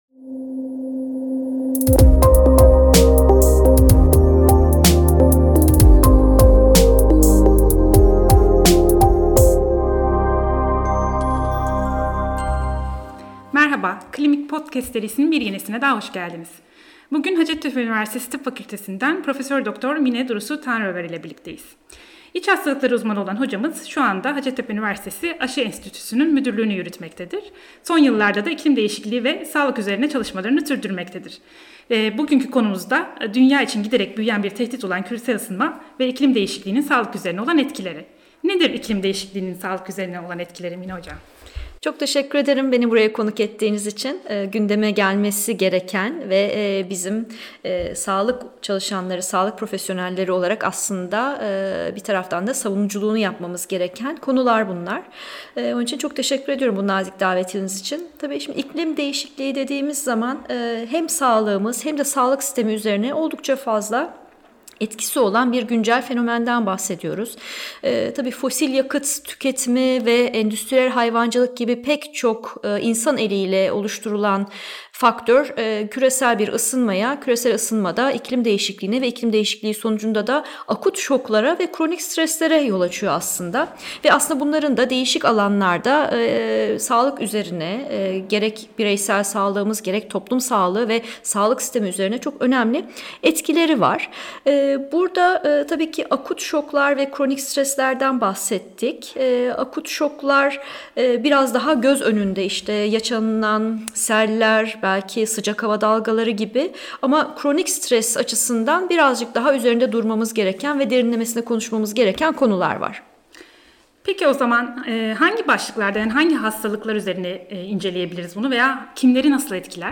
Bugün hocamızla dünya için giderek büyüyen bir tehdit olan küresel ısınma ve iklim değişikliğinin sağlık üzerine olan etkileri üzerine konuştuk.